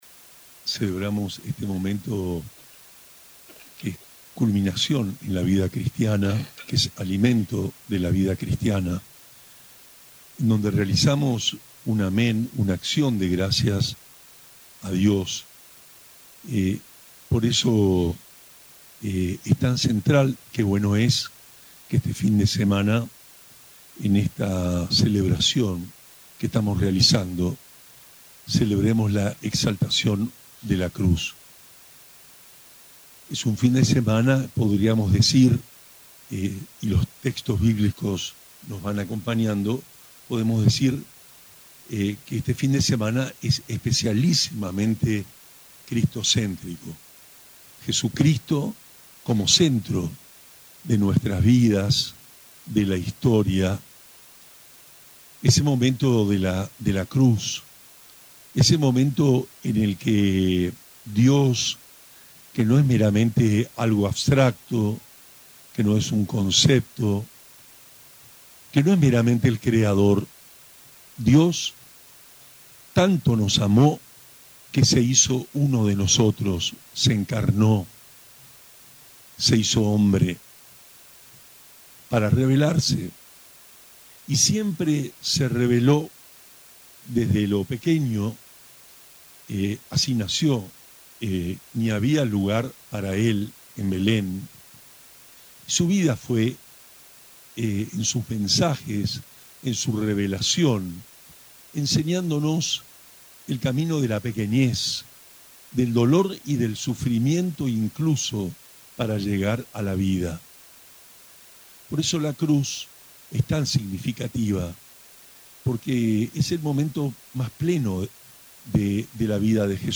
La jornada concluyó a las 19 con la Santa Misa presidida por monseñor Juan Rubén Martínez, obispo de Posadas, quien en su mensaje destacó el sentido profundo de los carismas como dones de Dios puestos al servicio de la comunidad.
13-09-HOMILIA-MISA-EXPOCARISMA.mp3